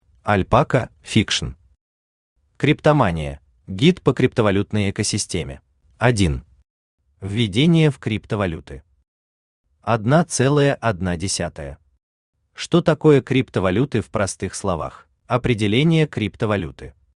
Aудиокнига Криптомания: Гид по криптовалютной экосистеме Автор Альпака Фикшн Читает аудиокнигу Авточтец ЛитРес.